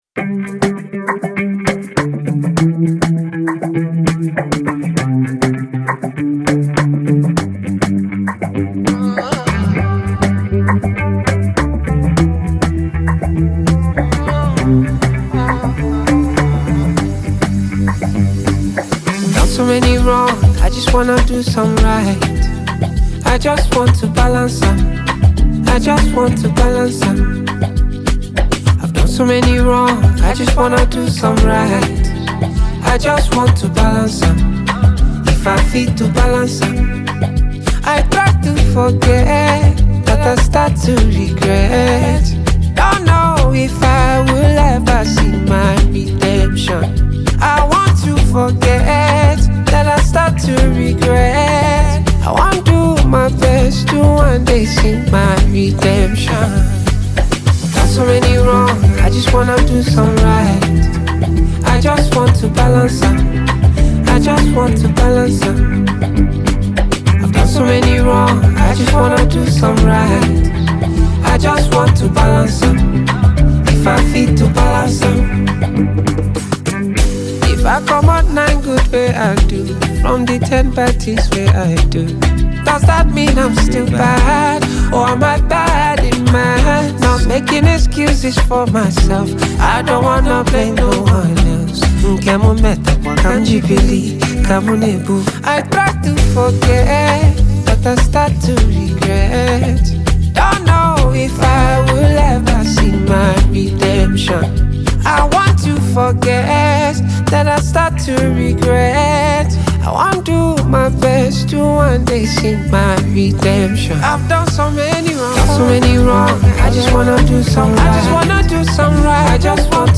highlife track
a good highlife tune